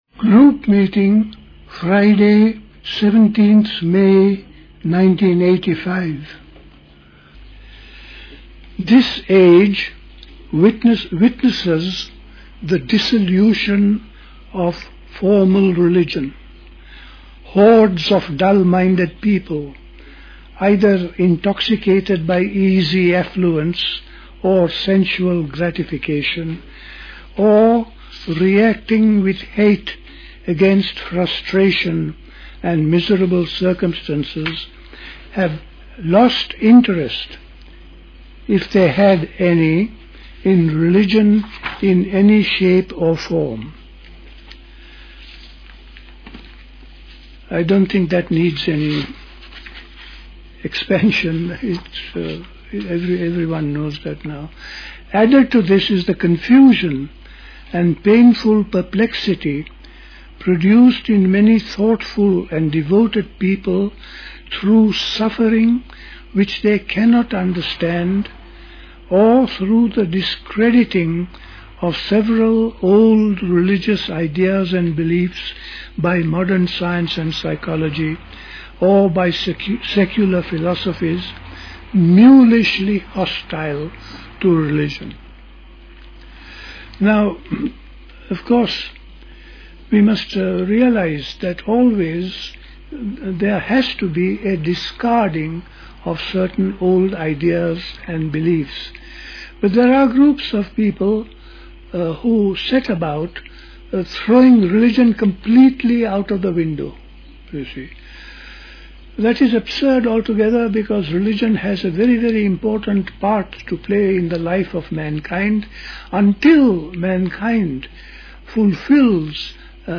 at Dilkusha, Forest Hill, London on 17th May 1985